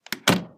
door_close.ogg